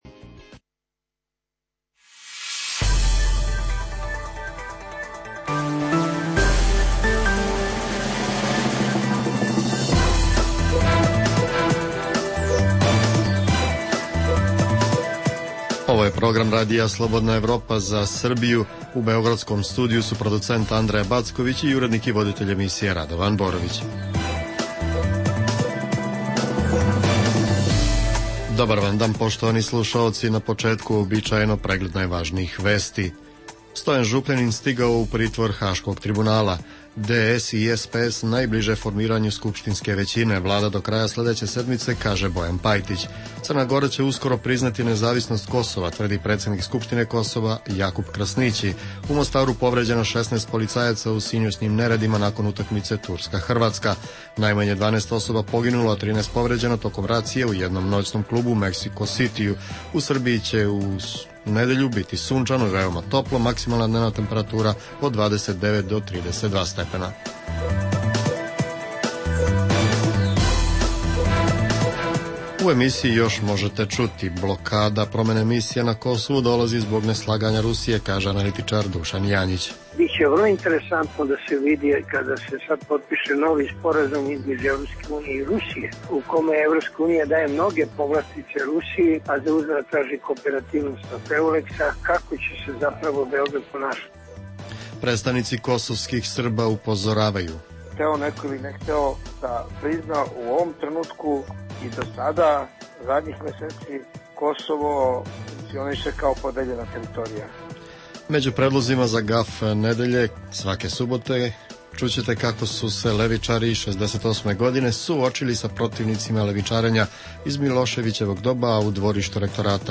Emisija namenjena slušaocima u Srbiji. Sadrži lokalne, regionalne i vesti iz sveta, tematske priloge o aktuelnim dešavanjima iz oblasti politike, ekonomije i slično, te priče iz svakodnevnog života ljudi, kao i priloge iz sveta.